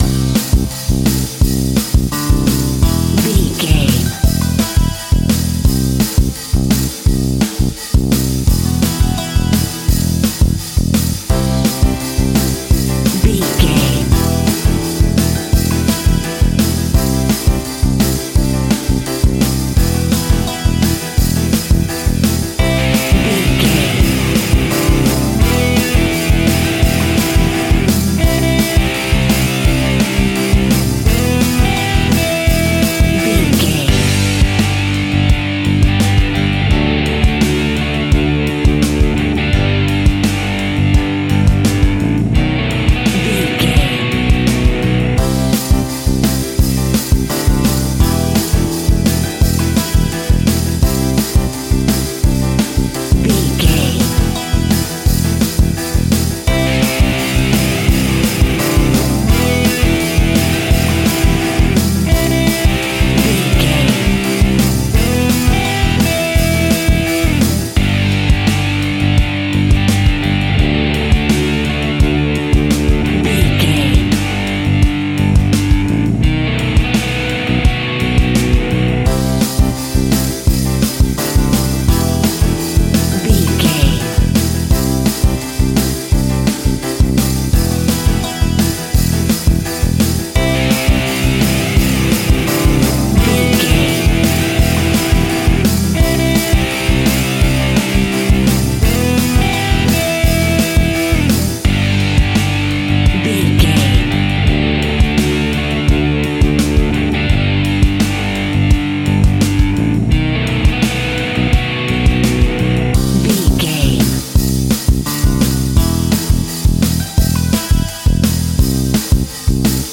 Epic / Action
Fast paced
Aeolian/Minor
pop rock
indie pop
fun
energetic
uplifting
cheesy
instrumentals
guitars
bass
drums
piano
organ